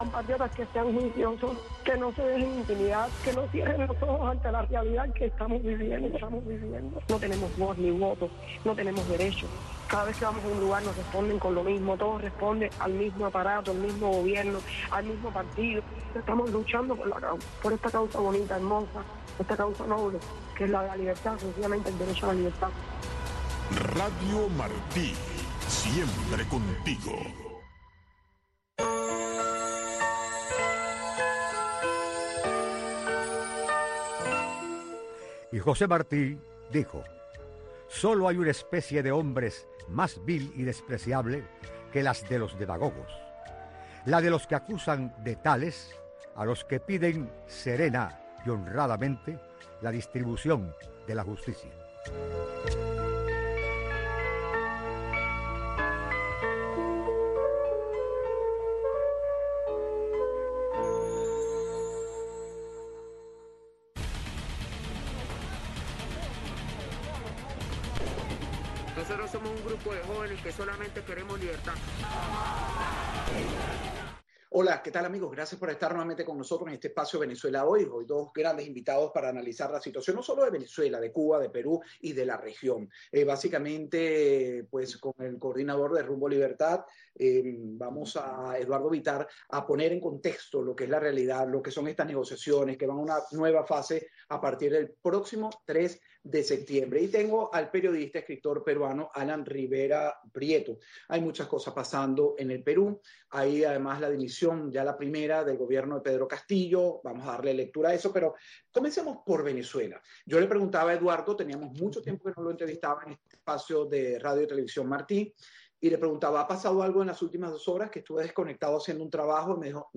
Un espacio donde se respeta la libertad de expresión de los panelistas y estructurado para que el oyente llegue a su propia conclusión. De lunes a viernes a las 11:30 am, y retransmitido a las 9:00 pm.